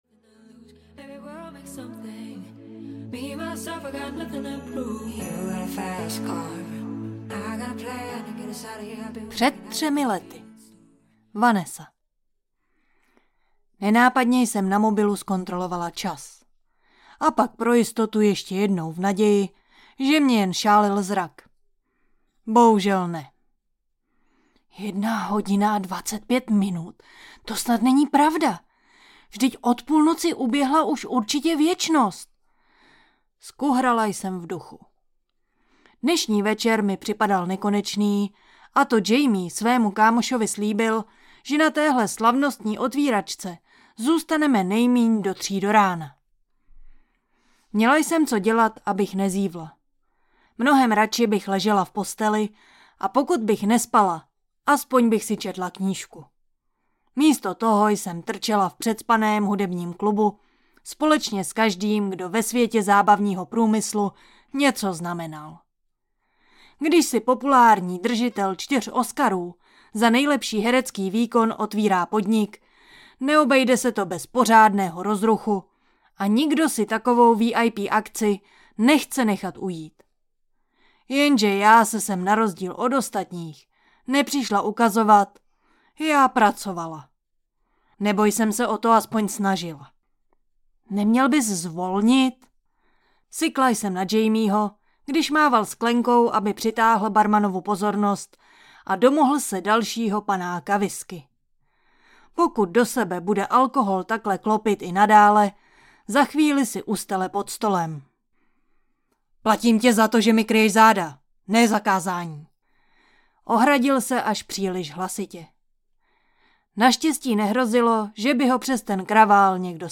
Formulista audiokniha
Ukázka z knihy